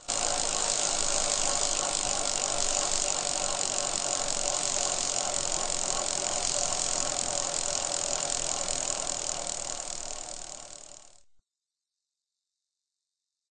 wheel_spin.ogg